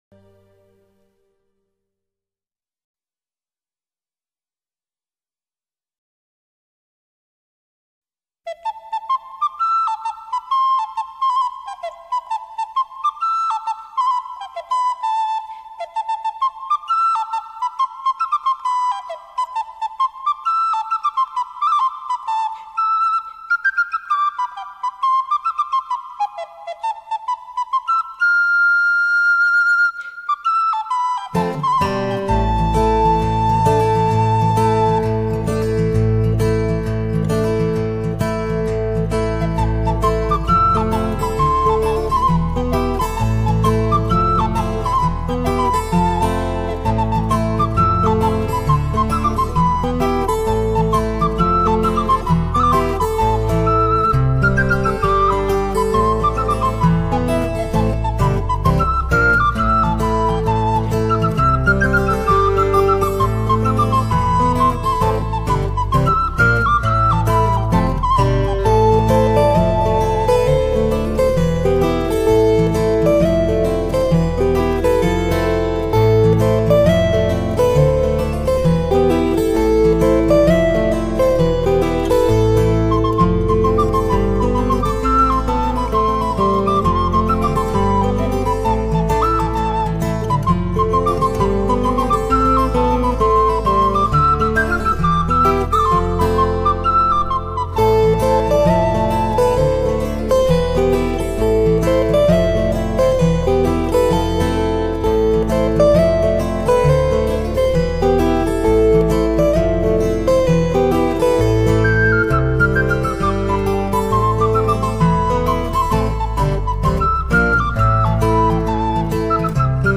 Guitar, oboe, English horn, percussion.